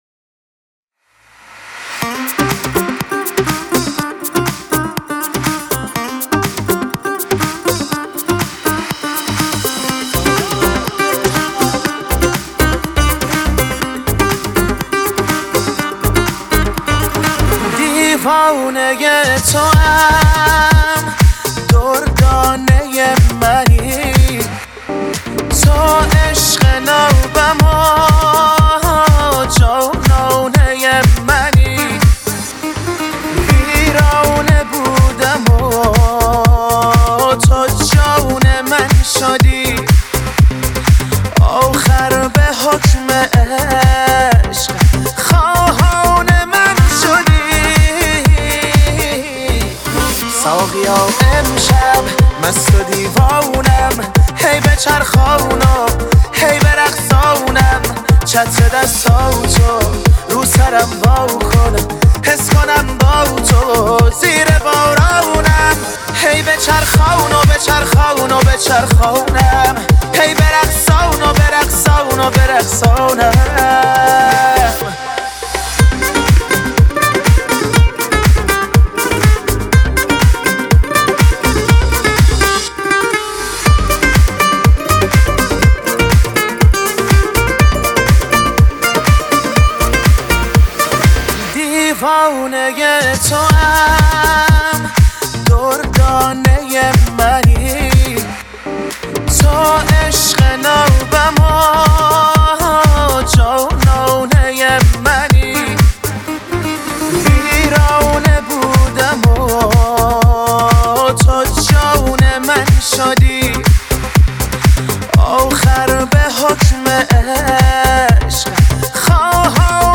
خواننده موسیقی پاپ
نوازنده سه تار